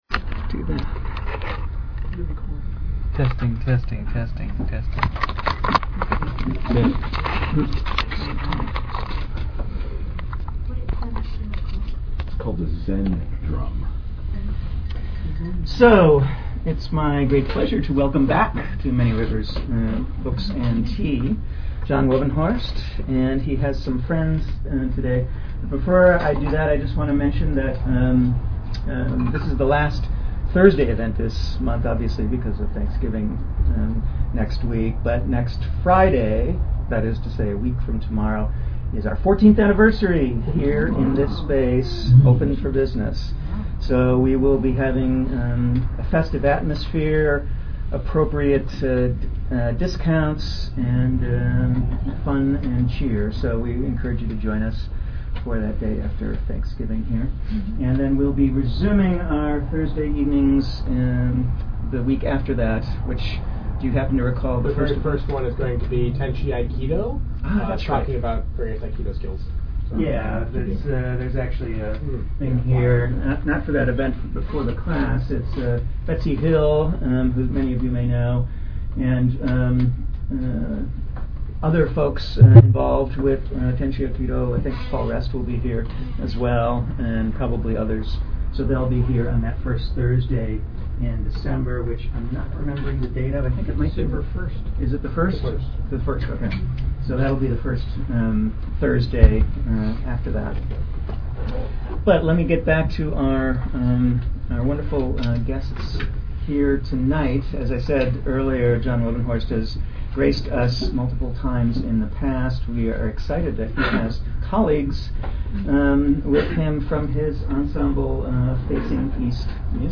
Archive of an event at Sonoma County's largest spiritual bookstore and premium loose leaf tea shop.
Classical Indian music meets jazz
Bansuri
unique blend of classical Indian ragas and modern jazz elements
Zendrum
bass
keyboards
improvisation is an essential element in the group with each member being a master of their instrument